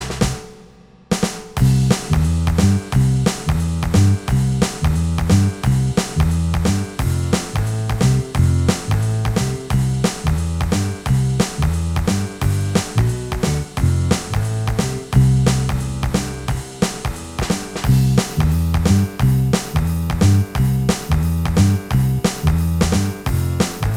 Minus Guitars Pop (1960s) 2:15 Buy £1.50